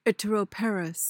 PRONUNCIATION:
(IT-uh-ro-PAR-uhs)